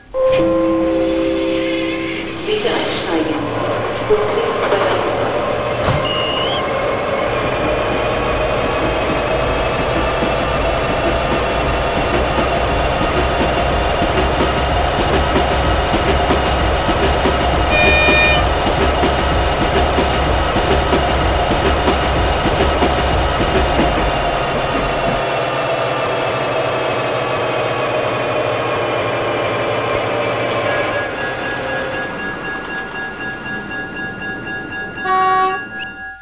Märklin 60986 SoundDecoder mSD3 Diesellok-Geräusch NEM-652 - H0
Märklin 60986 Demo-Sound.mp3